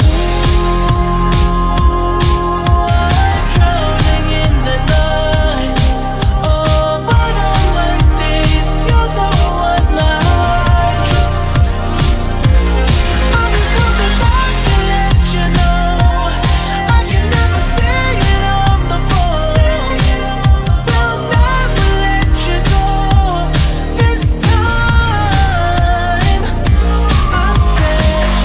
Genere: pop,hit